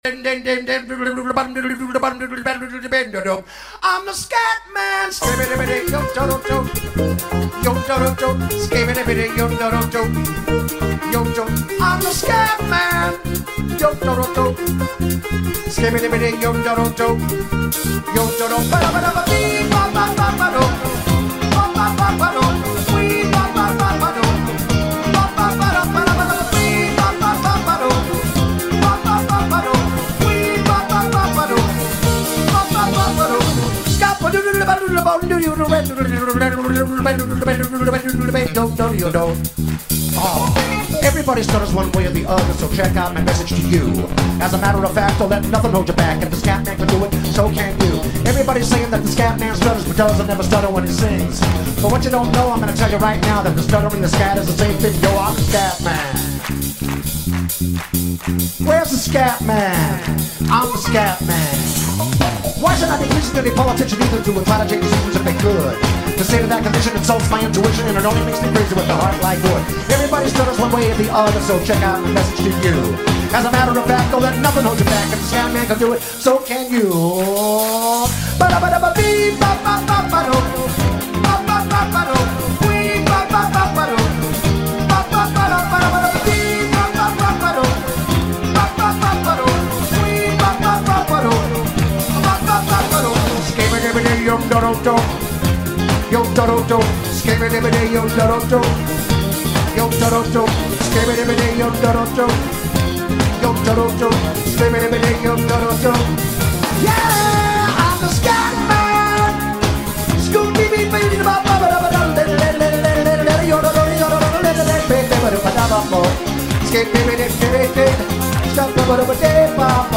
#90smusic